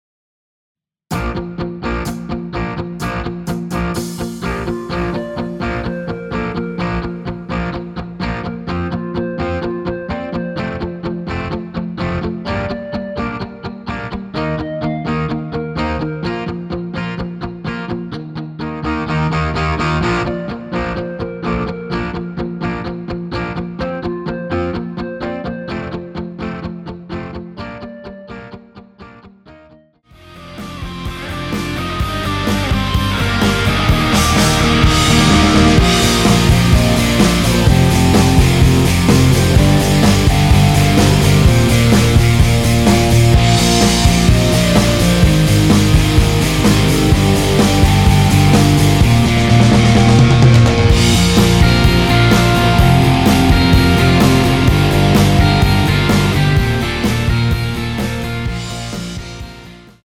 전주없이 노래 시작 하는 곡이라 전주 만들어 놓았습니다.
(하이 햇 소리 끝나고 노래 시작 하시면 됩니다.)
원키에서(-1)내린 멜로디 포함된 MR입니다.
Bb
앞부분30초, 뒷부분30초씩 편집해서 올려 드리고 있습니다.
중간에 음이 끈어지고 다시 나오는 이유는